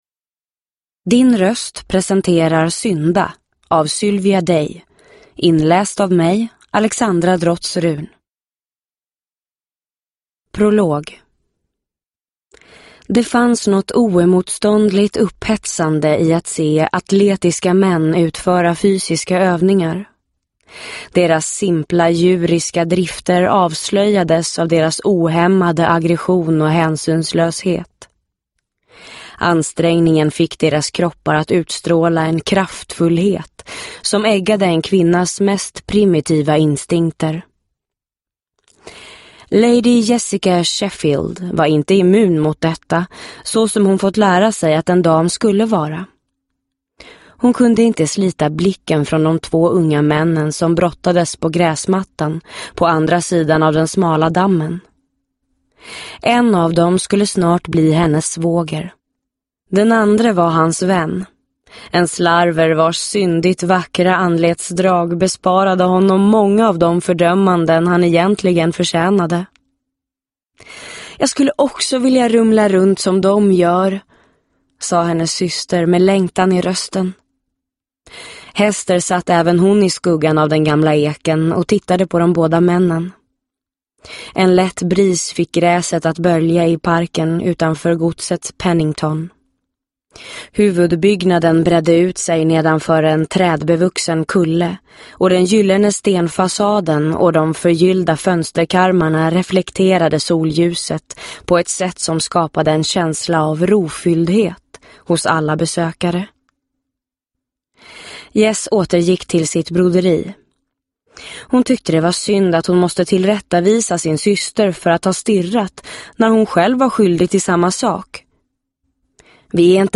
Synda – Ljudbok – Laddas ner